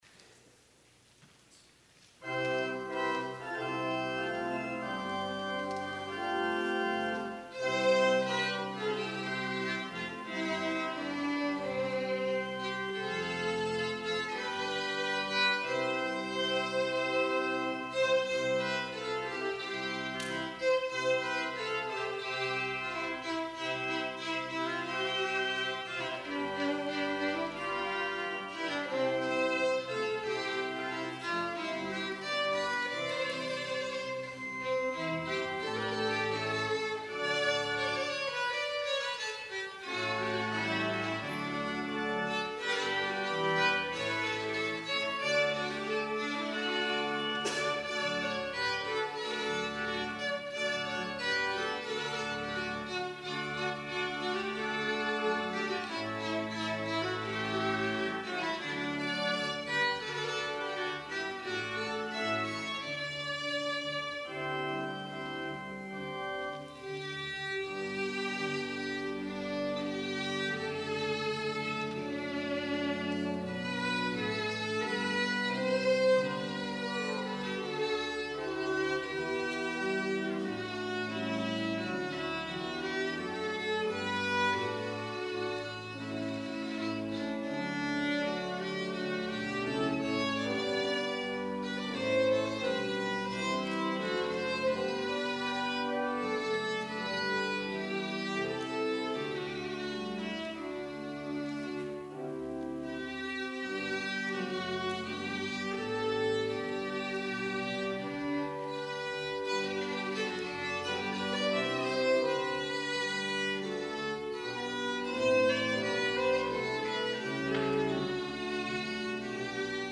Selection:  Christmas Medley Arranged by Gary Lanier
violin